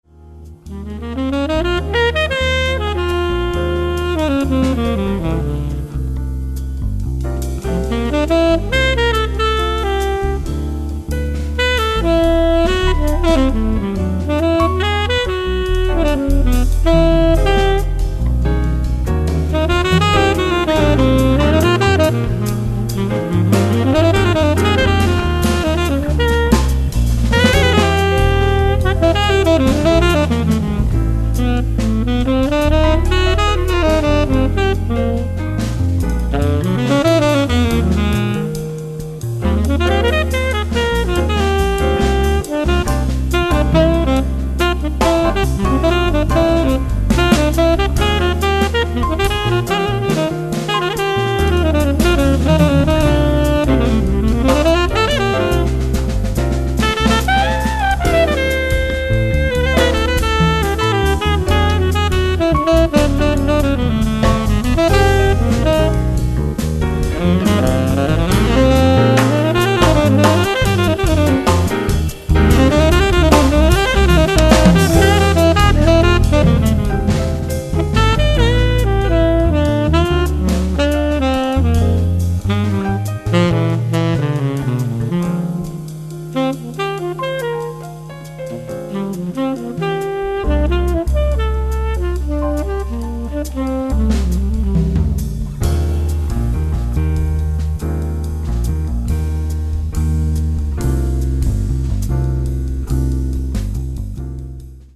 sax soprano, flauto, flauto in sol
sax tenore